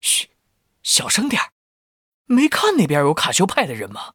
文件 文件历史 文件用途 全域文件用途 Timothy_amb_02.ogg （Ogg Vorbis声音文件，长度4.1秒，97 kbps，文件大小：49 KB） 文件说明 源地址:游戏解包语音 文件历史 点击某个日期/时间查看对应时刻的文件。 日期/时间 缩略图 大小 用户 备注 当前 2019年1月24日 (四) 04:34 4.1秒 （49 KB） 地下城与勇士  （ 留言 | 贡献 ） 分类:蒂莫西(地下城与勇士) 分类:地下城与勇士 源地址:游戏解包语音 您不可以覆盖此文件。